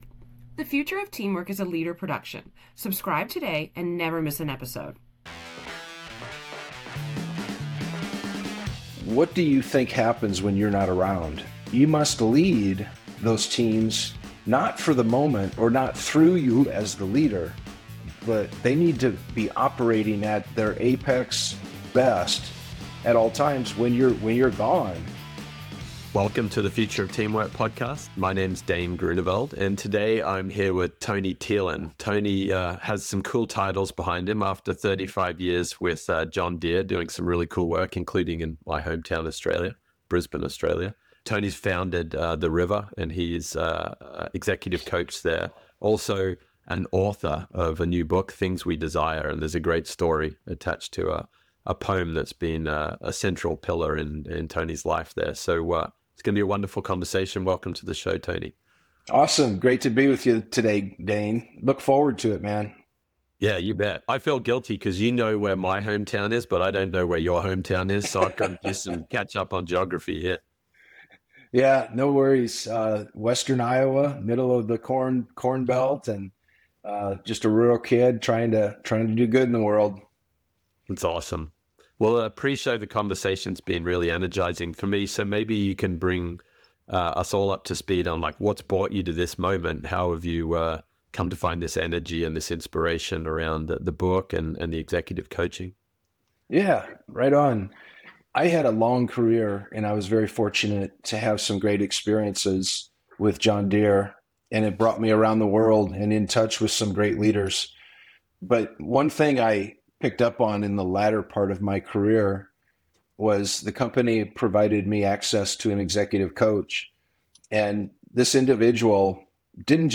It’s a conversation about moving beyond micromanagement, creating trust deposits in relationships, and giving talented people freedom on the “how” while aligning on values and outcomes.